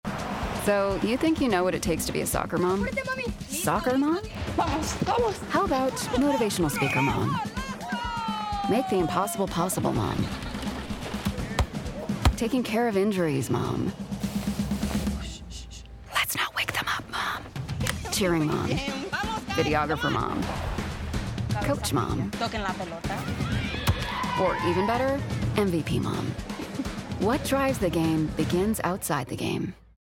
From witty and wry to warm and compassionate, I've got you.
Ford Commercial